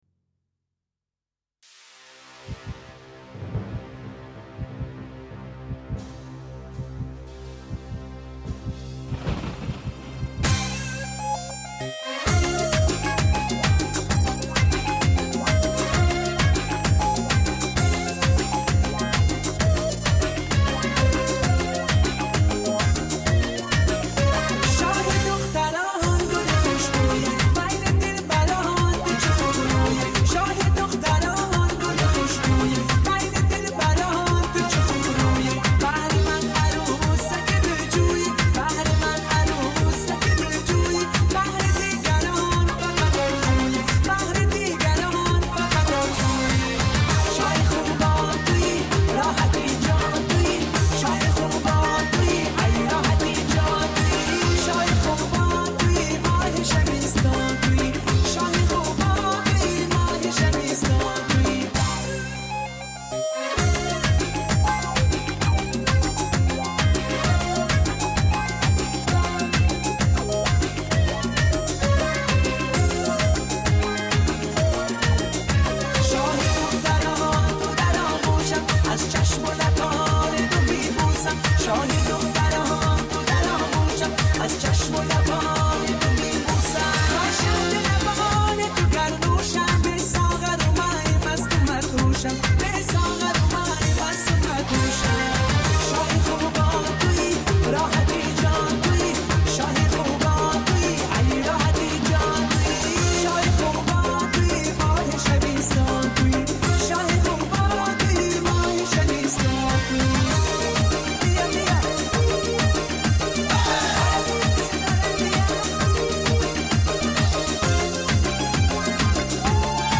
таджики